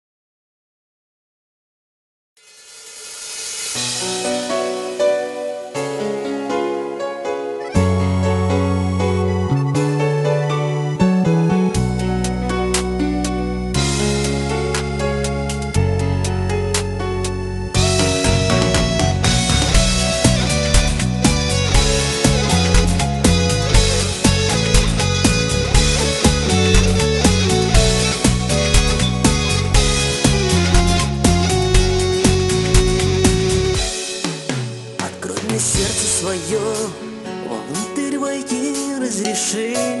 Красивый мелодичный шансон